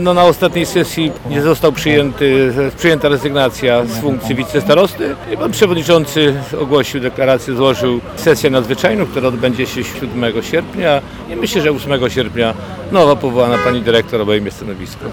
Mówi starosta mielecki Zbigniew Tymuła.